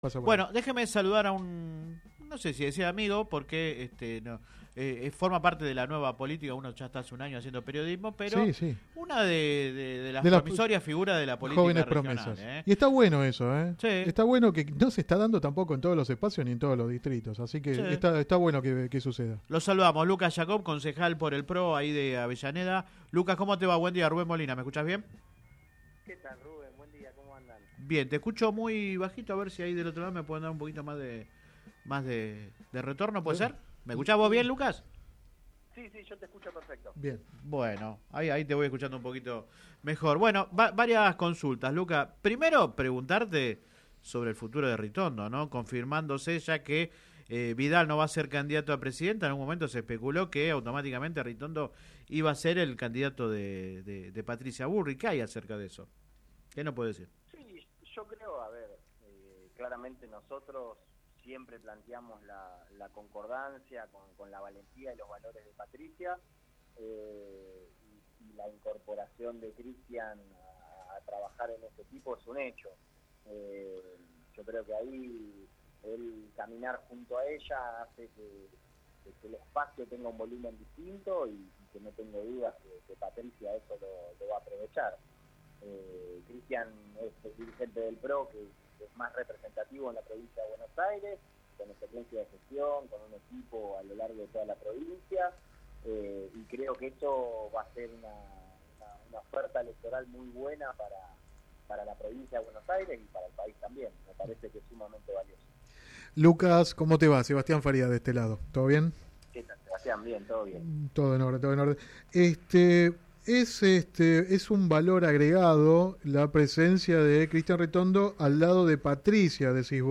El edil del PRO habló en exclusivo con el programa radial Sin Retorno (lunes a viernes de 10 a 13 por GPS El Camino FM 90 .7 y AM 1260).